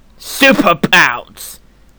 pusuperbouncevoice.wav